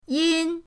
yin1.mp3